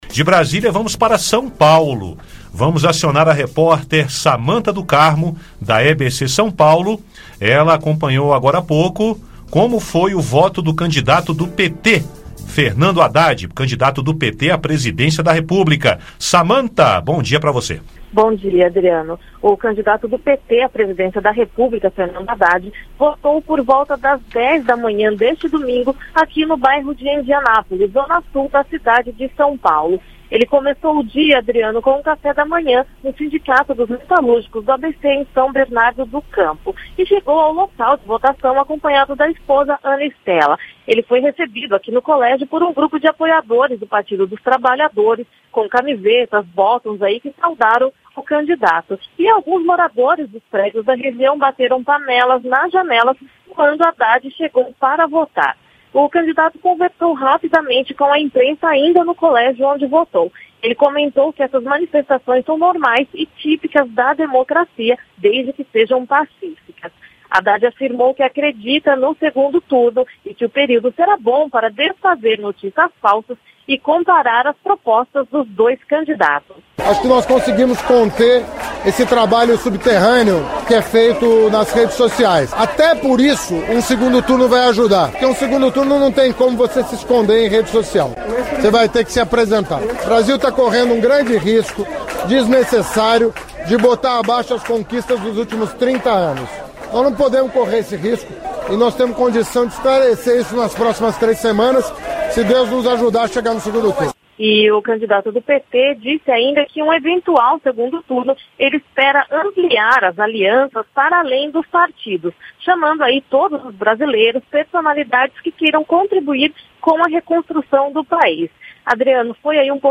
Entrevista Fernando Haddad vota em São Paulo Haddad votou acompanhado da esposa.